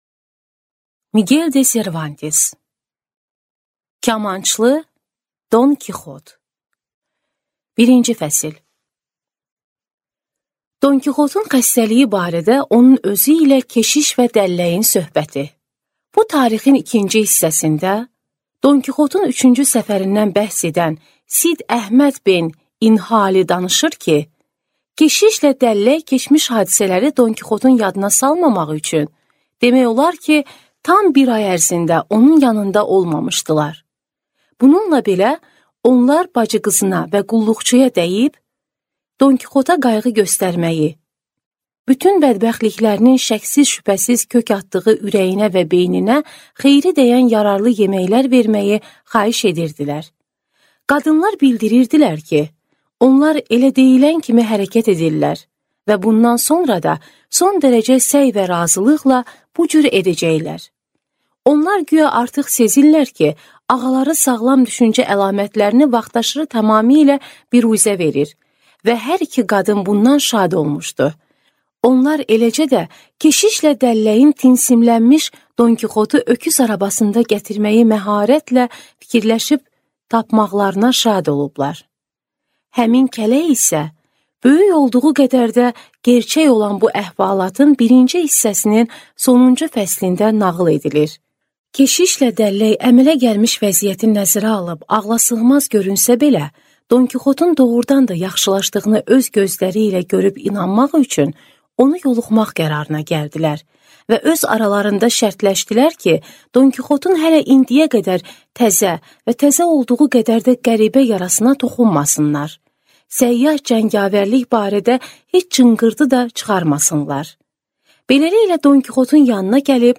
Аудиокнига Don Kixot | Библиотека аудиокниг